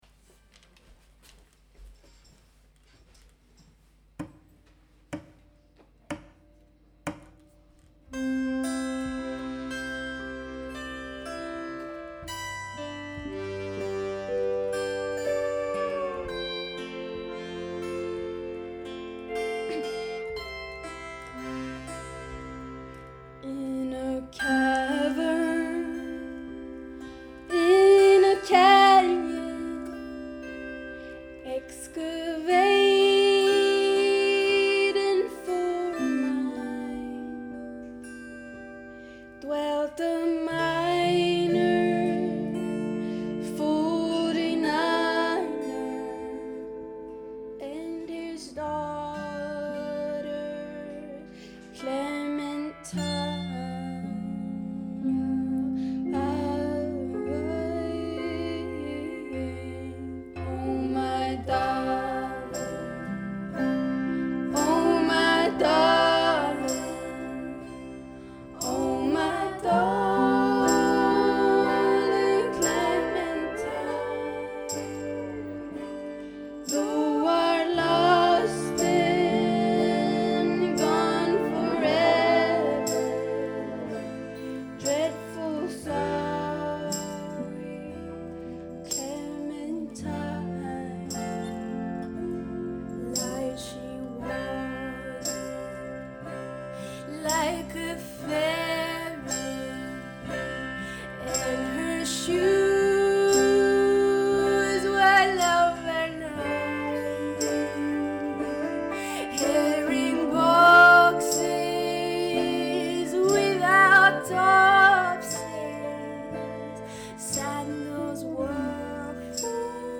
giovane duo composto dalle gemelle
È una cover della classica ballata folk Oh My Darling, Clementine, e se la scelta può sembrare a prima vista stucchevole, vincete la pigrizia e premete play.